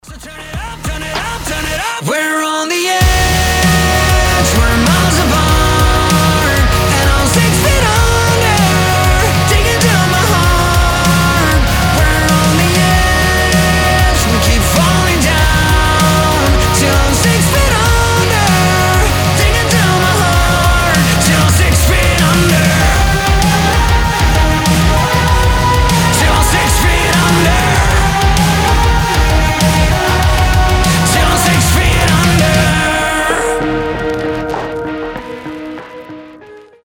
громкие
мощные
Alternative Rock